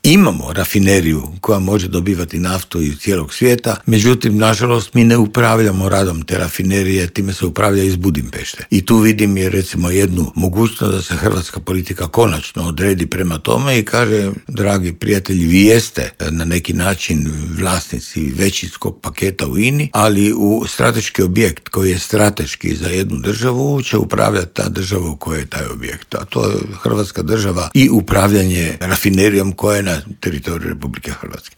ZAGREB - Dok svijet pozorno prati hoće li doći do mirnog okončanja rata u Ukrajini, energetski stručnjak Davor Štern u Intervjuu Media servisa poručuje da bi u slučaju ublažavanja europskih sankcija trebalo dogovoriti fiksirane cijene nafte i plina.